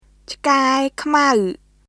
(1) ត្រីធំ (魚＋大きい＝大きい魚) [トライ・トム trəi tʰom] (2) ឆ្កែខ្មៅ (犬＋黒い＝黒い犬) [チカエ・クマウ cʰkae kʰmaw] (3) របស់ខ្ញុំ (の＋私＝私の) [ロボホ・クニョム rɔ̀bɔh kʰɲom] (4) អាធំ (の＋大きい＝大きいの) [アー・トム ʔaː tʰom] (5) អាខ្មៅ (の＋黒い＝黒いの) [アー・クマウ ʔaː kʰmaw]